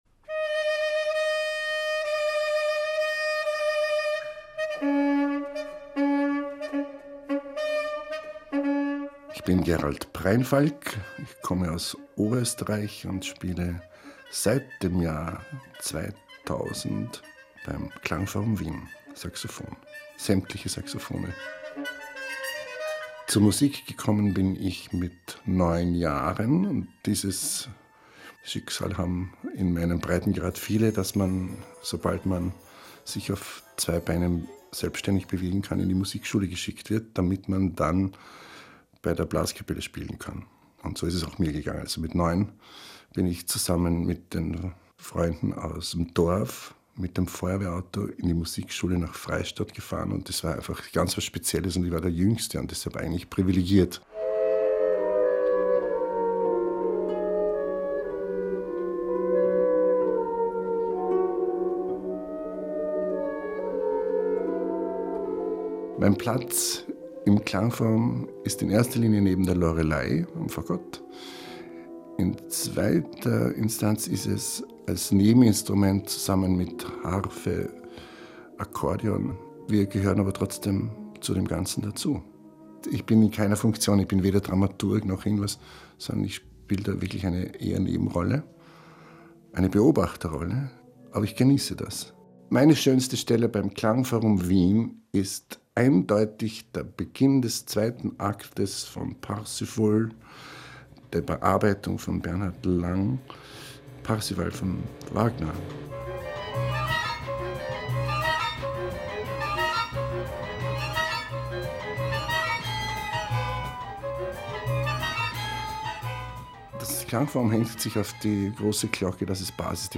Saxophon